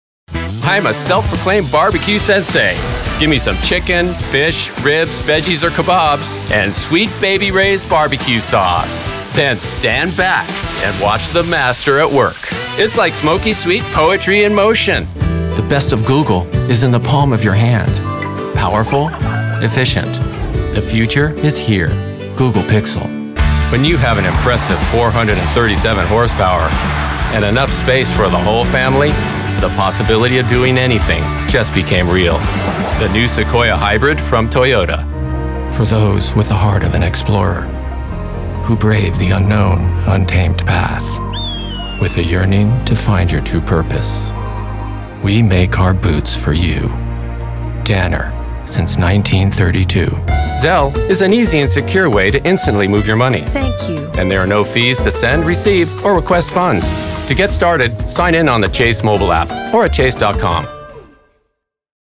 Voice Actor
Demo Recorded From My Home Studio
Audio Technica AT875R Microphone
voice-demo.mp3